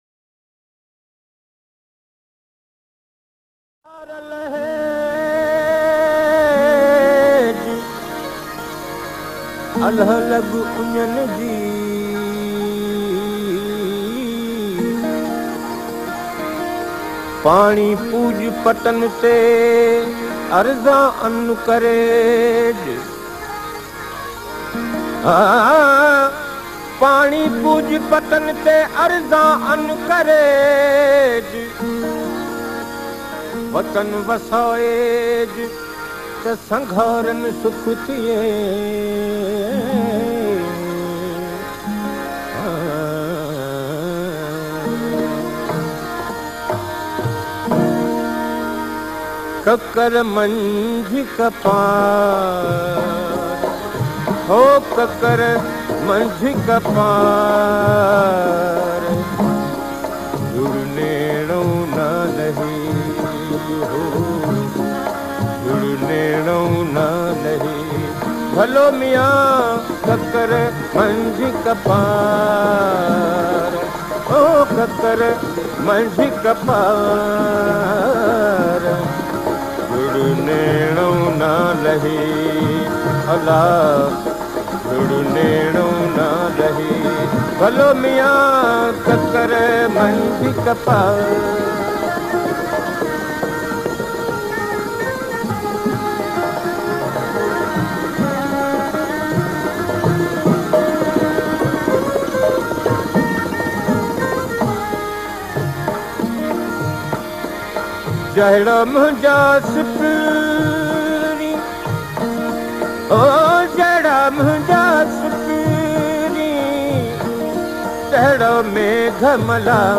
Sindhi Rajasthani Sufi Music